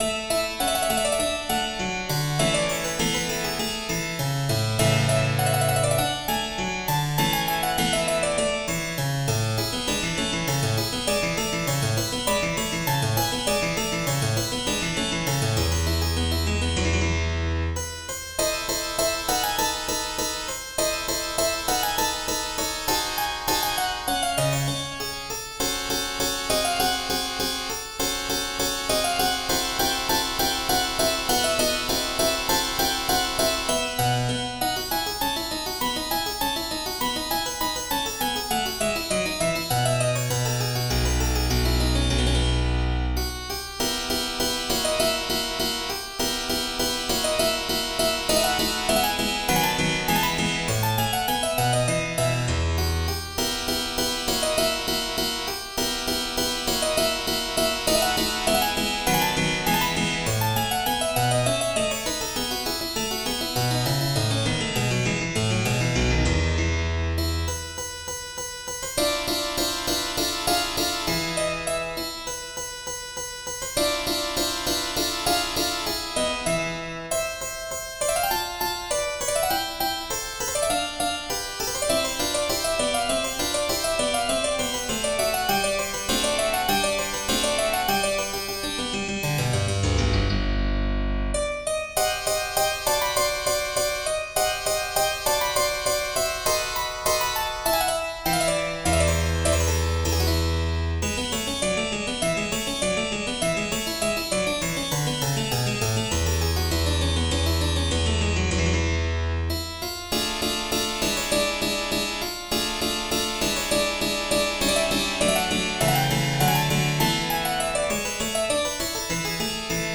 in A Major: Allegro